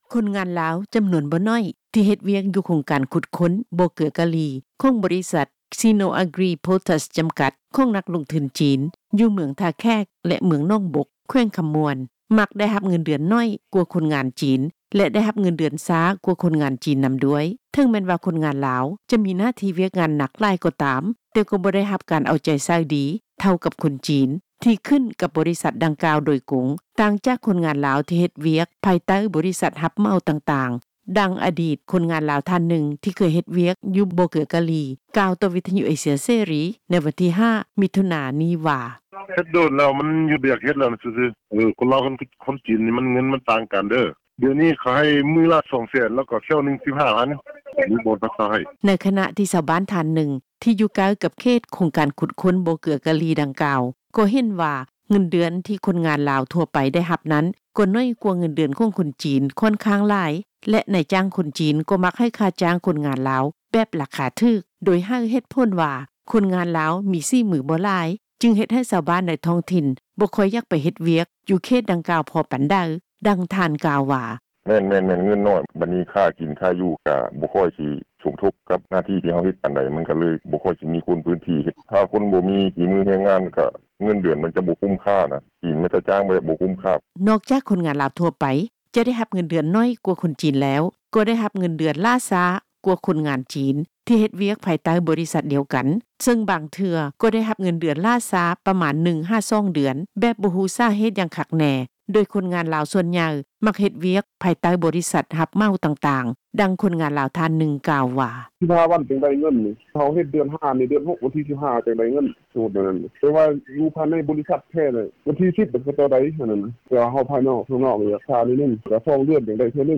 ດັ່ງ ອະດິດຄົນງານລາວ ທ່ານໜຶ່ງ ທີ່ເຄີຍເຮັດວຽກຢູ່ບໍ່ເກືອກາລີ ກ່າວຕໍ່ ວິທຍຸເອເຊັຽເສຣີ ໃນວັນທີ 5 ມີຖຸນາ ນີ້ວ່າ
ດັ່ງ ຄົນງານລາວ ທ່ານໜຶ່ງກ່າວວ່າ:
ດັ່ງ ເຈົ້າໜ້າທີ່ຜະແນກແຮງງານ ແລະ ສະຫວັດດີການສັງຄົມ ແຂວງຄໍາມ່ວນ ນາງໜຶ່ງກ່າວວ່າ: